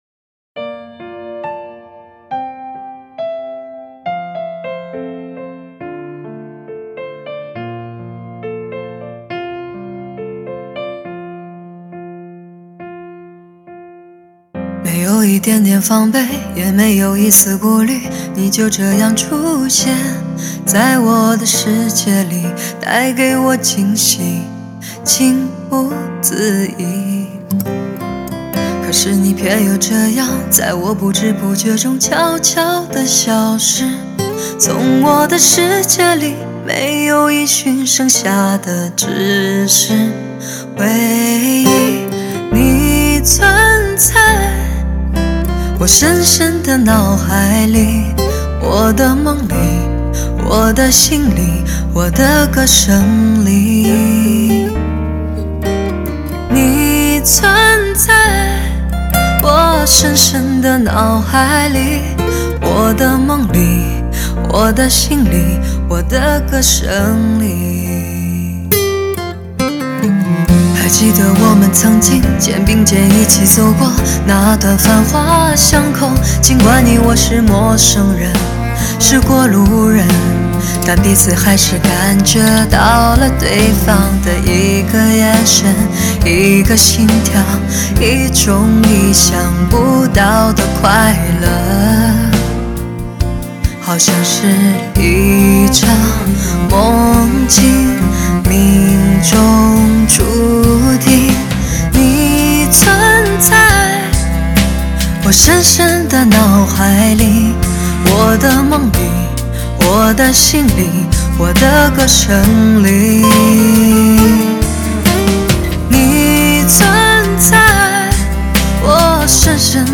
全新发烧女声无损音乐
感情丰富的演唱极具撼人的感染力！
极品流行发烧人声测试，无与伦比的极致人声，让心灵无限释放